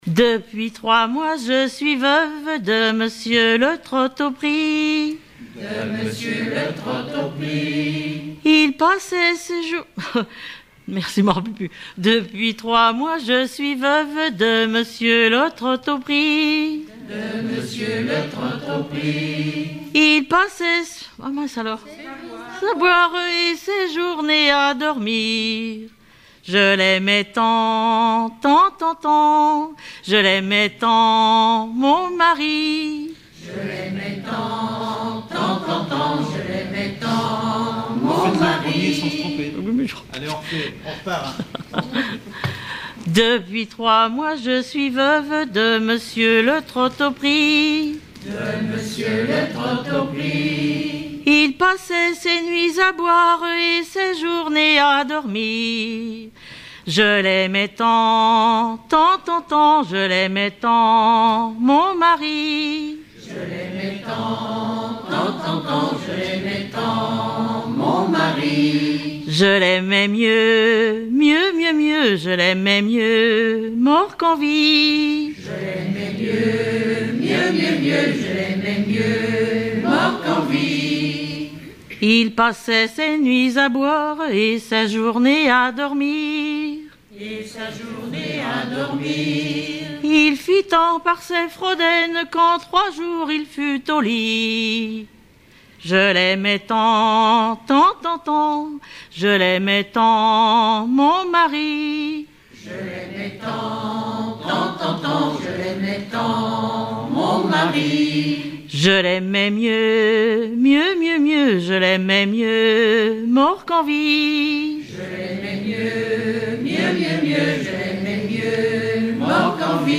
Genre laisse
Enquête Arexcpo en Vendée-C.C. Deux Lays
Chansons traditionnelles et populaires
Pièce musicale inédite